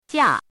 怎么读
jiā